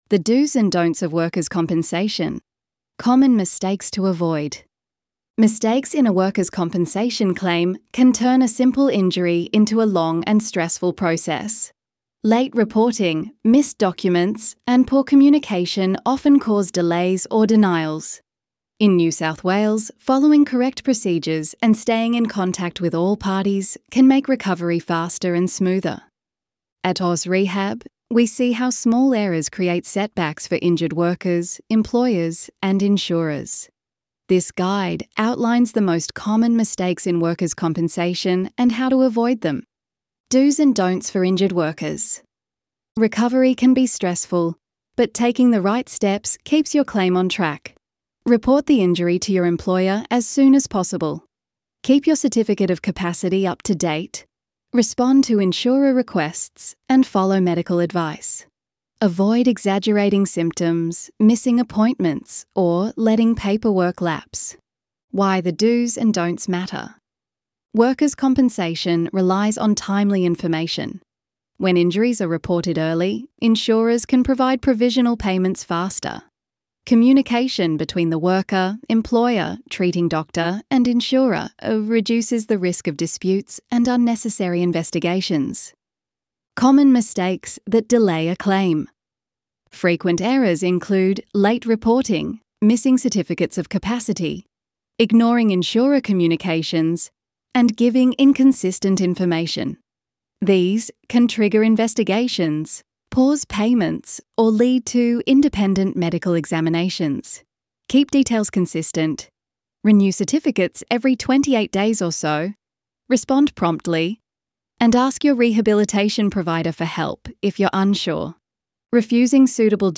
Single-host narration
ElevenLabs_The_Dos_and_Donts_of_Workers_.wav